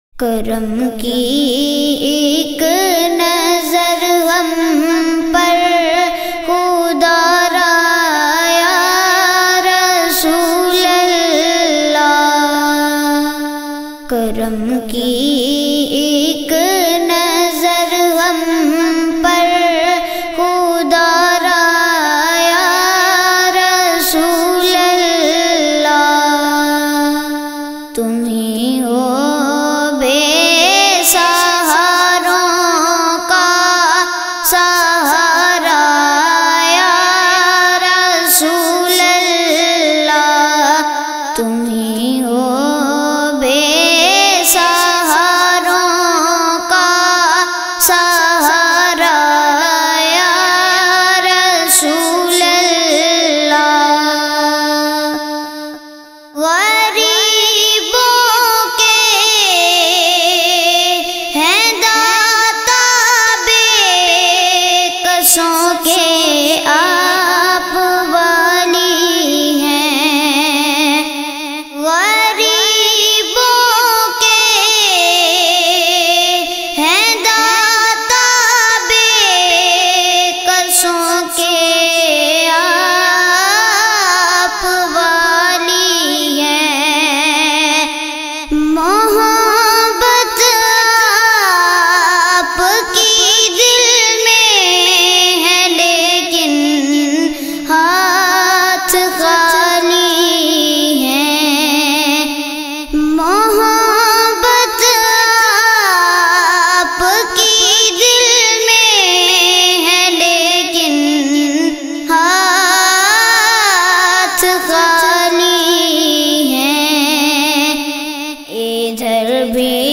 Heart Touching Naat Sharif